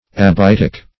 Search Result for " abietic" : The Collaborative International Dictionary of English v.0.48: Abietic \Ab`i*et"ic\, a. Of or pertaining to the fir tree or its products; as, abietic acid, called also sylvic acid.
abietic.mp3